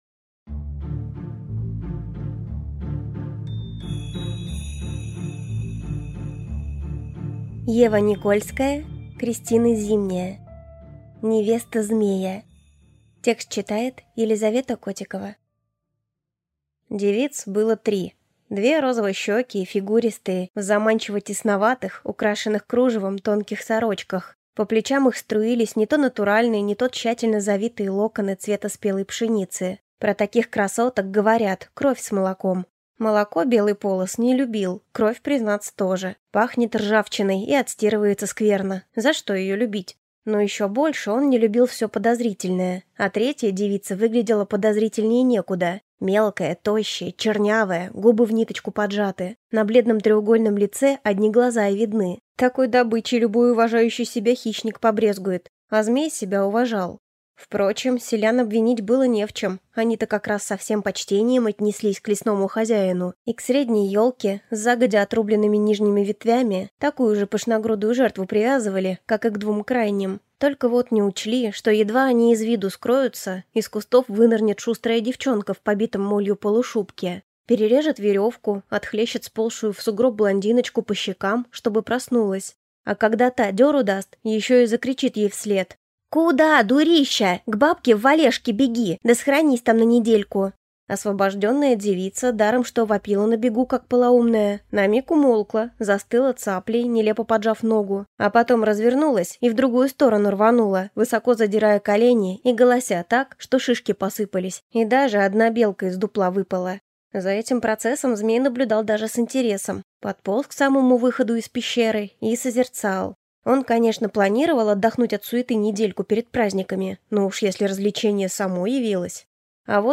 Капитан Волков. Назад в СССР – 2 (слушать аудиокнигу бесплатно) - автор Павел Барчук